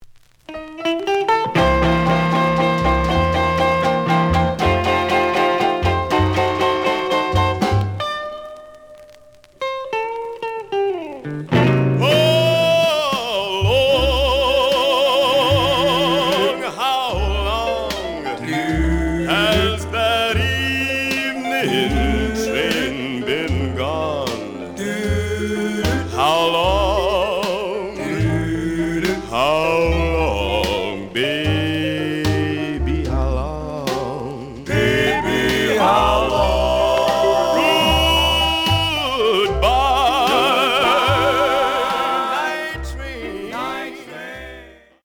The audio sample is recorded from the actual item.
●Genre: Rhythm And Blues / Rock 'n' Roll
Slight click noise on later half of B side due to a bubble.